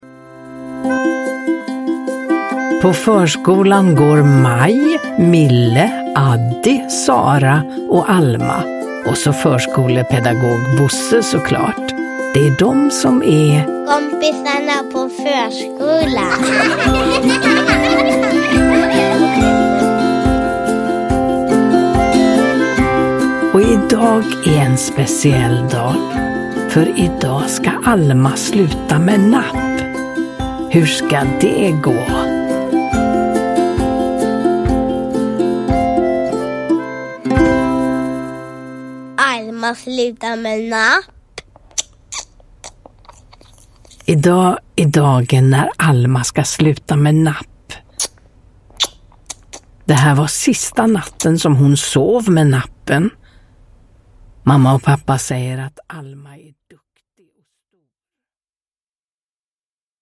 Alma slutar med napp – Ljudbok
Uppläsare: Ulla Skoog